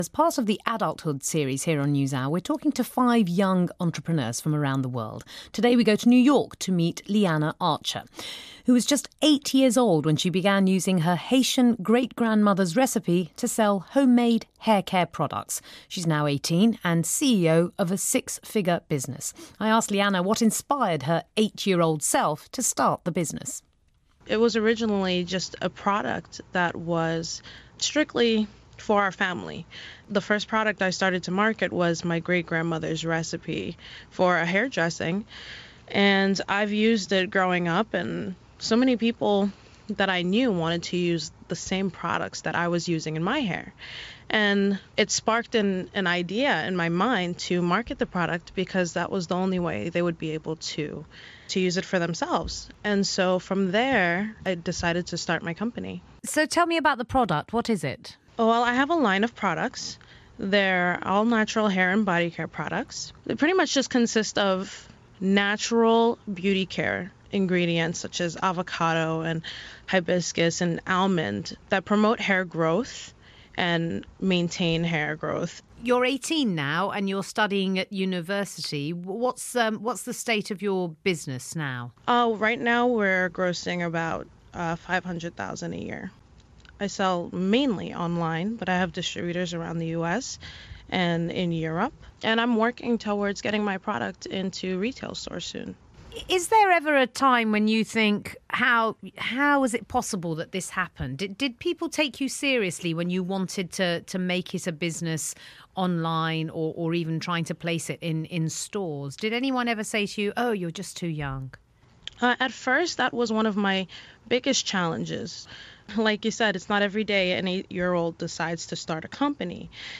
Razia Iqbal presenting.